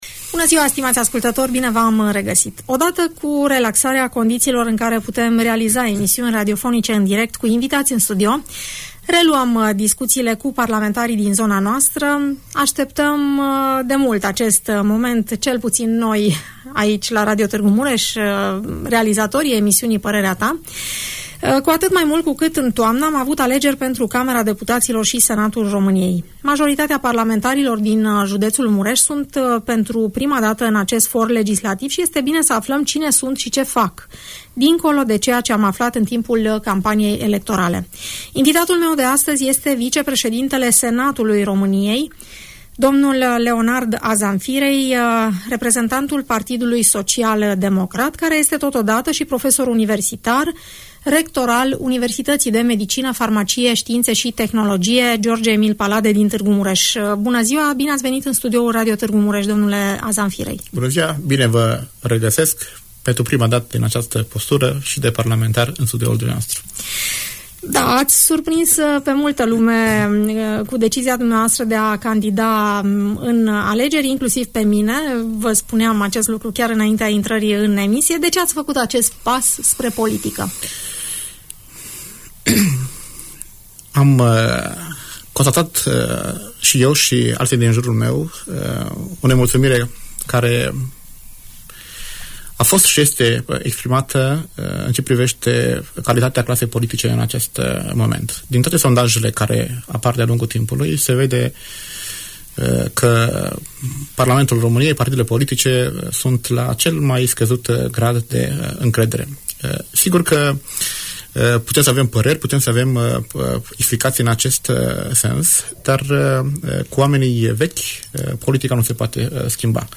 Vicepreședintele Senatului României, la Radio Tg. Mureș - Radio Romania Targu Mures
Leonard Azamfirei, vicepreședintele Senatului României din partea PSD, își prezintă proiectele în fața ascultătorilor Radio Tg Mureș.